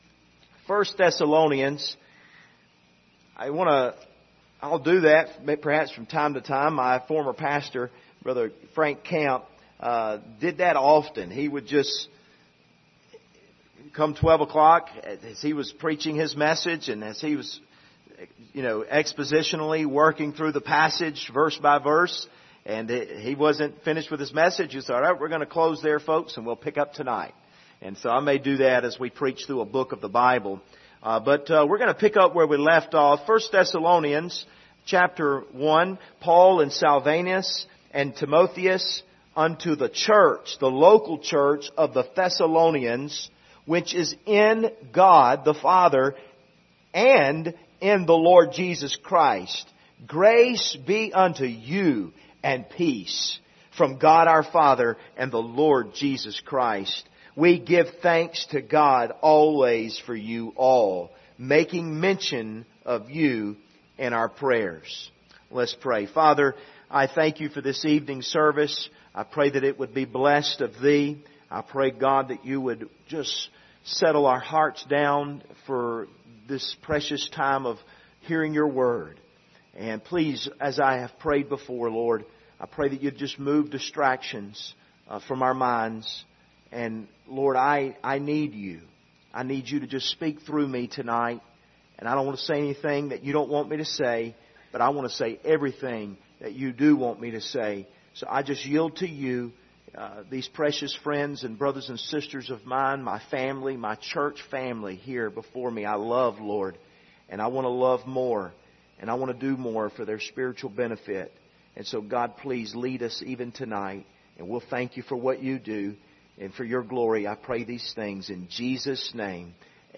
Passage: 1 Thessalonians 1:1-2 Service Type: Sunday Evening